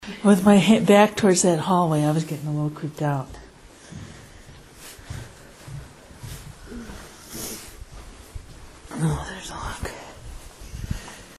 An abandoned Catholic seminary we
Old dorms hallway
Having come into this area to listen for phantom music reported by others, I heard nothing but recorded a strange rasping voice just after I stated that I felt creeped out by having my back to a long dark hallway.